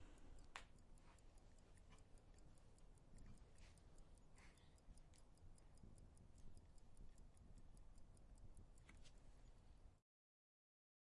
旧怀表的滴答声
描述：老怀表滴答作响：TickTock，手表滴答作响，齿轮转动，发条。微妙的声音，柔和。在Centurion南非的一个下午录制了Zoom H4n录音机，并被录制为学院声音设计项目的一部分。一块旧怀表用于录制声音